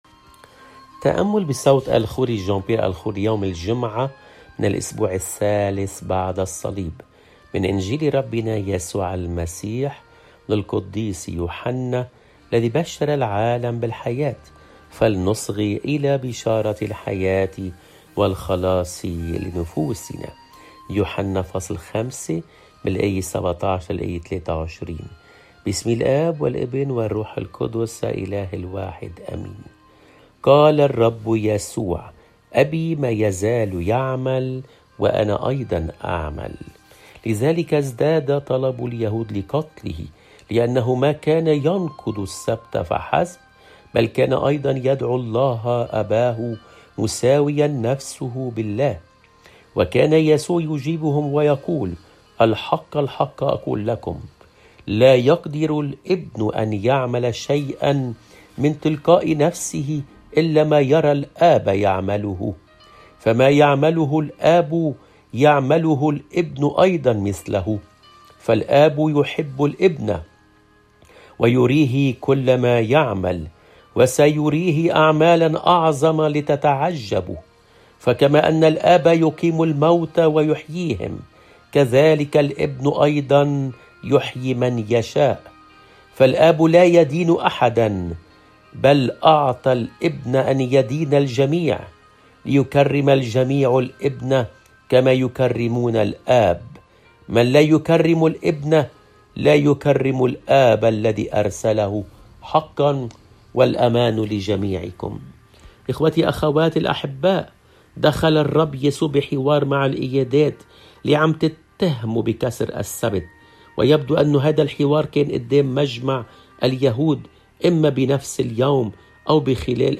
قراءات روحيّة يوميّة - ilahouna